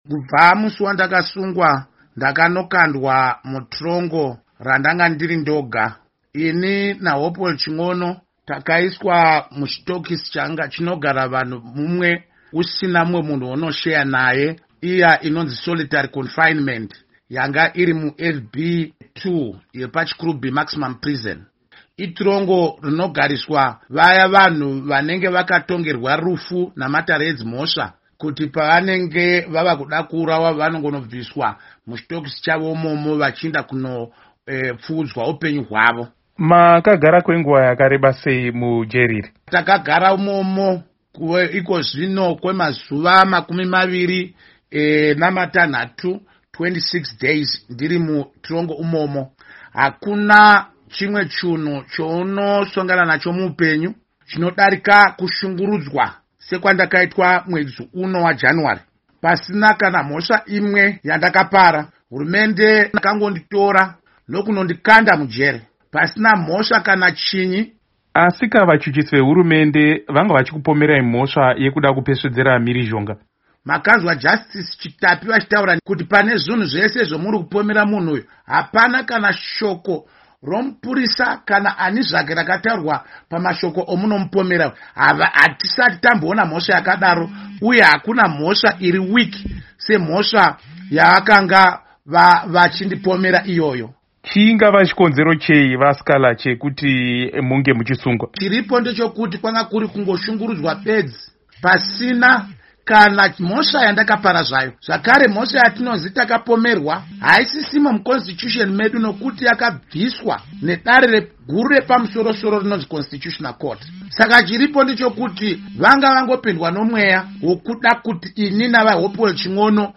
Hurukuo naVaJob Sikhala